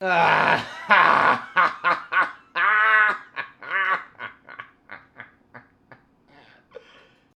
Ba-da-dum
Category 🤣 Funny
bad comedy drum fill funny joke one-liner positive sound effect free sound royalty free Funny